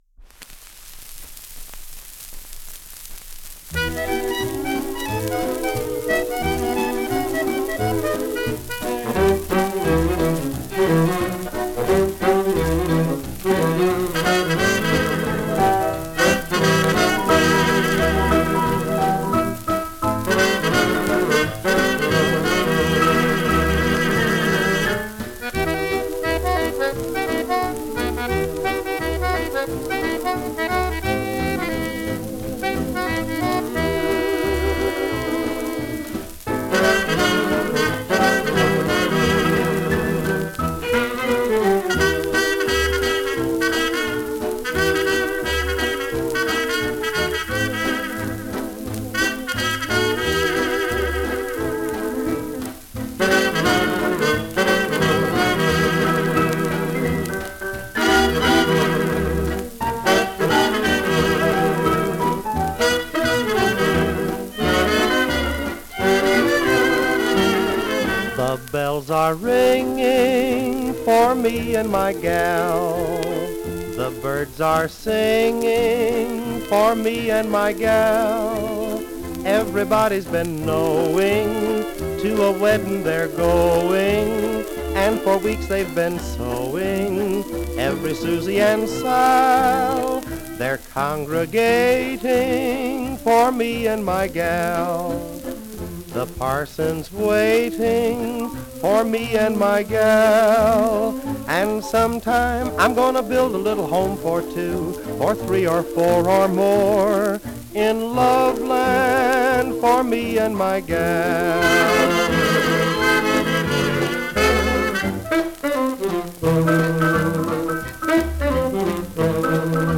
Genre: Fox-Trot.